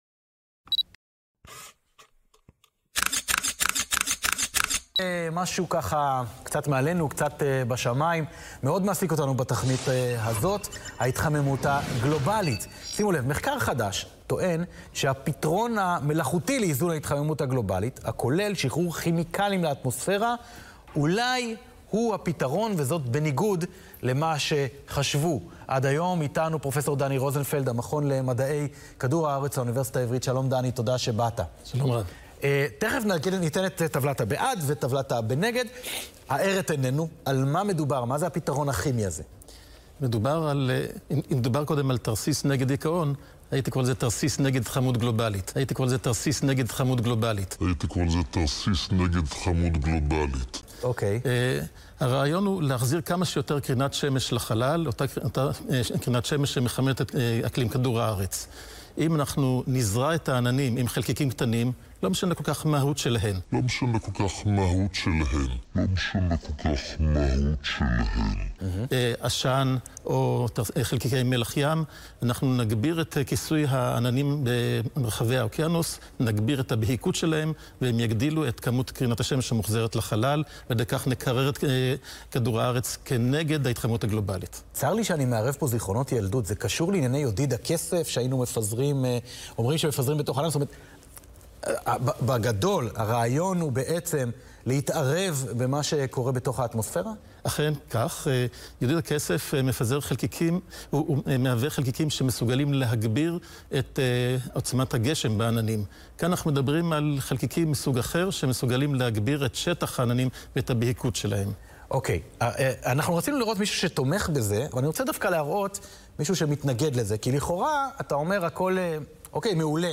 שודר ב- 17 למרץ, 2019, בתוכנית הטלויזיה “העולם היום” בערוץ ״כאן״.